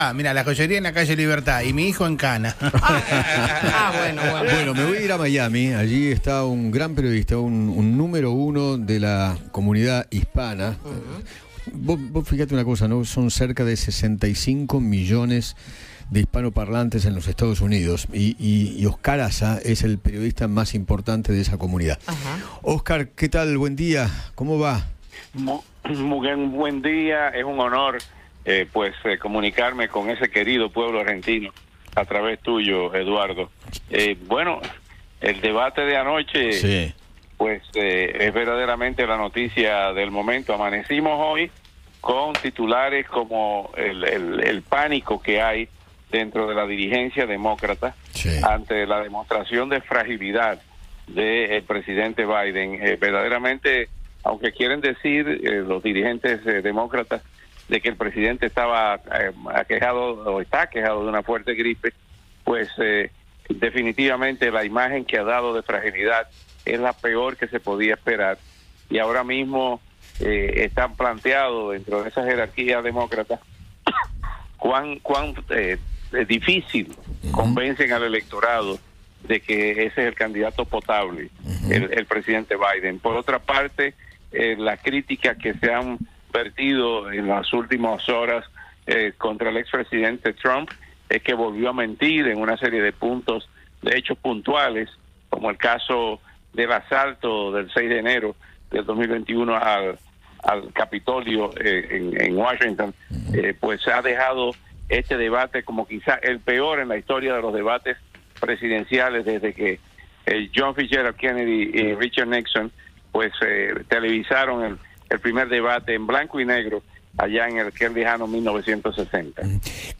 habló con Eduardo Feinmann sobre el debate presidencial entre Joe Biden y Donald Trump.